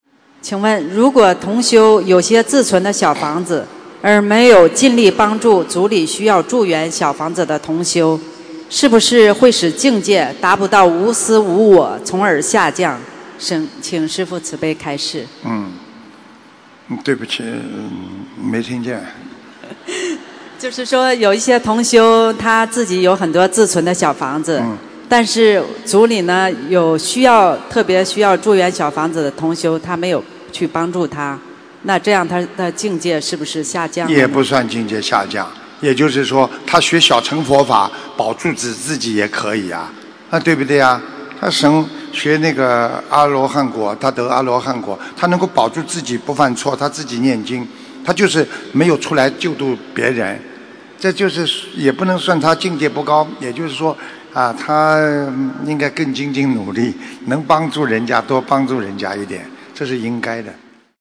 没结缘小房子给需要的同修，会否导致境界下降——弟子提问 师父回答--2017年马来西亚吉隆坡弘法解答会（1）博客 2017-09-17 & ...